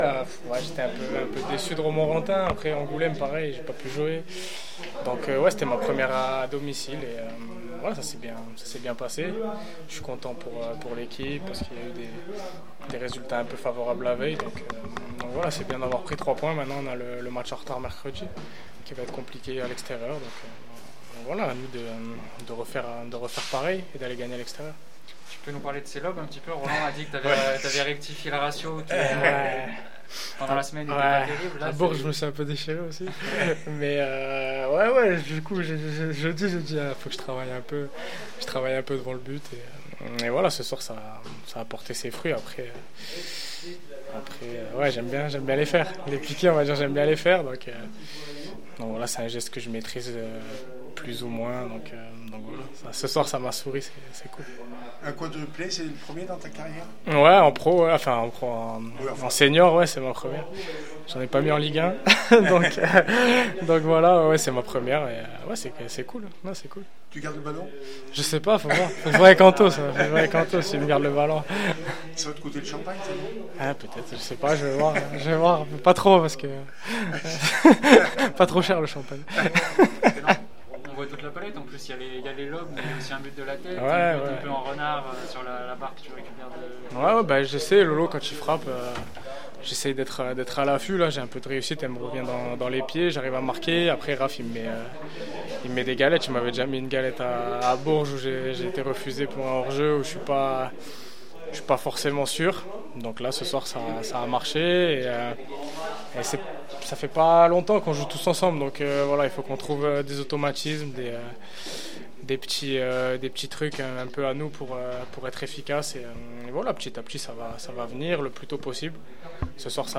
nationale 2 foot le puy foot 43 4-1 st montois réac après match 131120